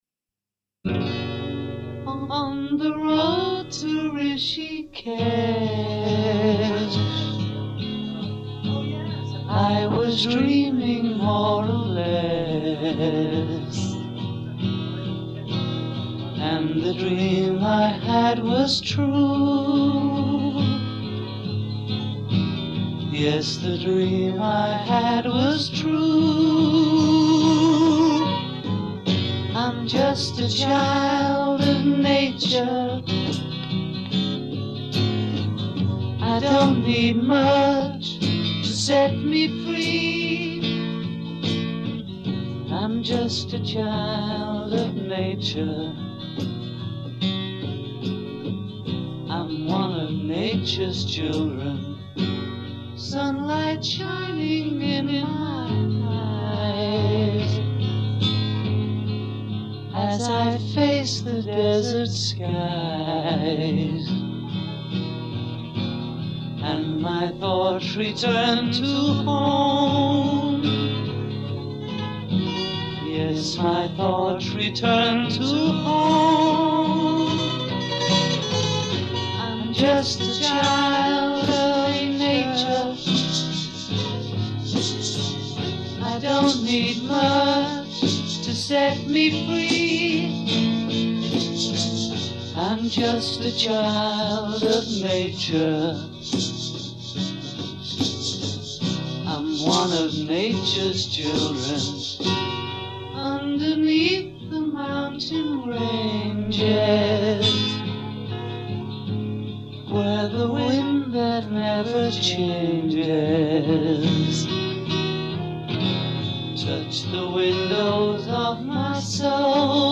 Demo Version